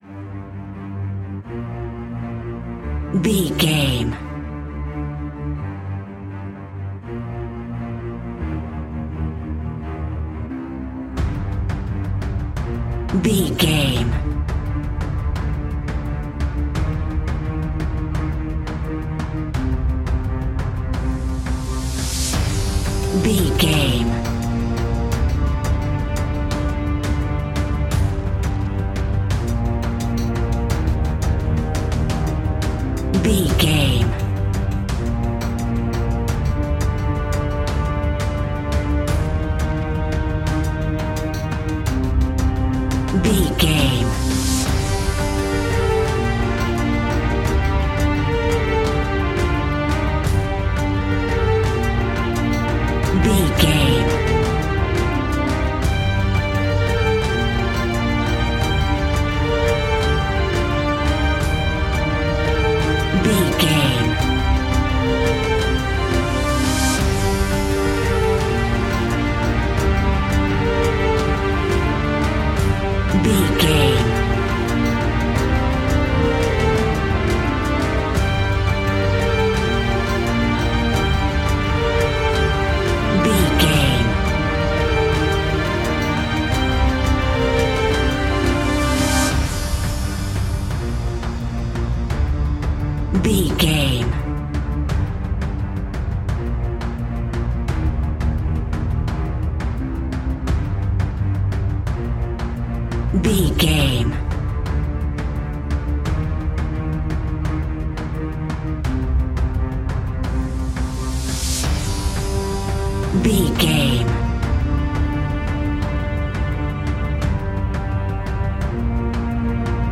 Aeolian/Minor
dramatic
epic
strings
percussion
synthesiser
brass
violin
cello
double bass